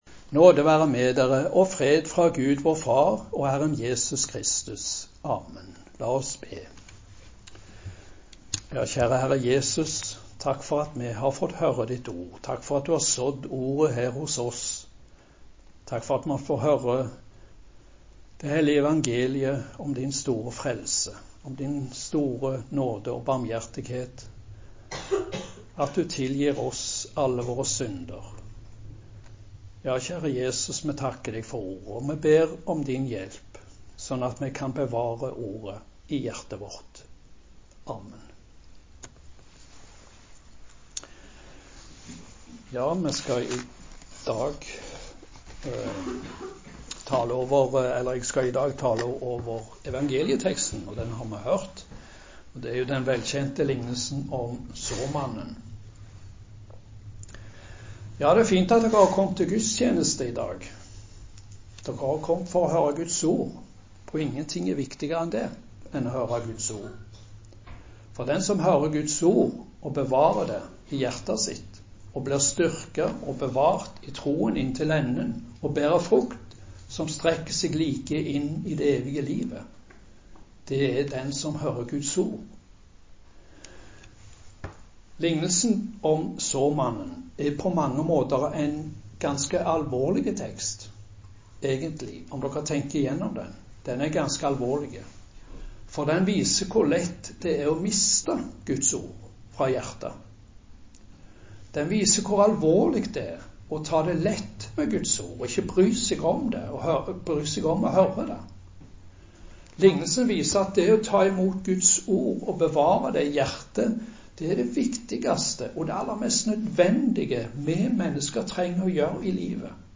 Preken på Såmannssøndag